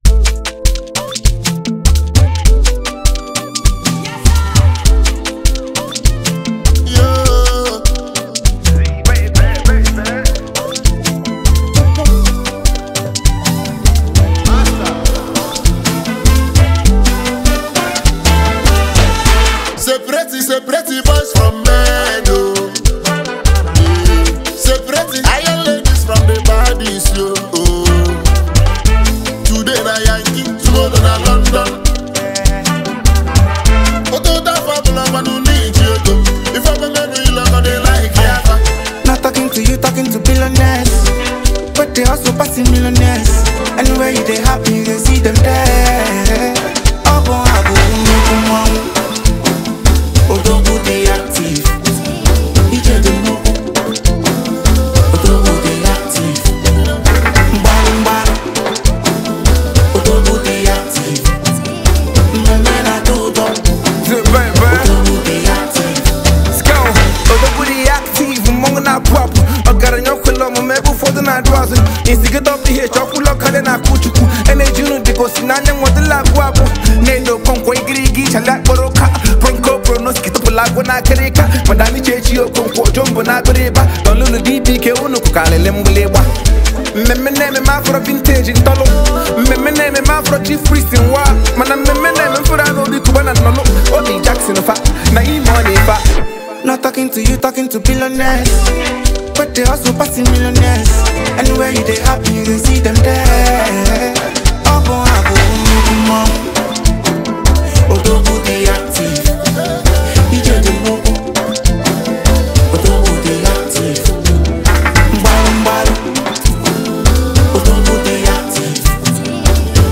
massive anthem